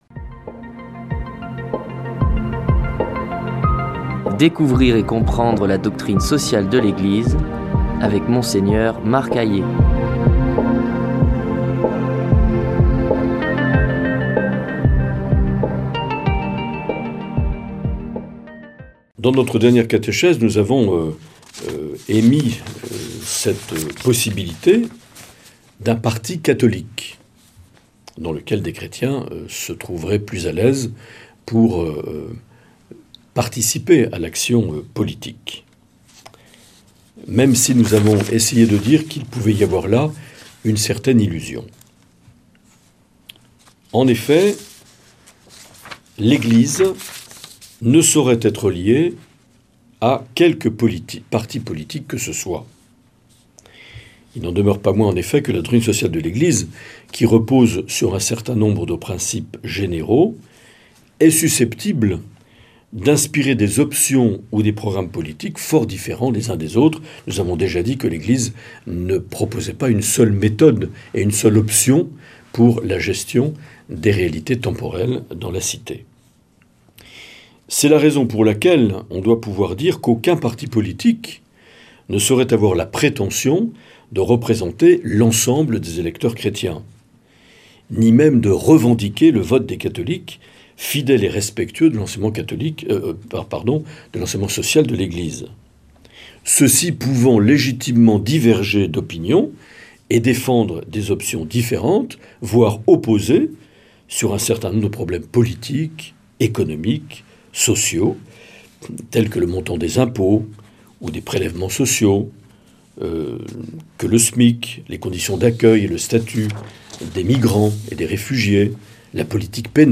Monseigneur Marc Aillet
Présentateur(trice)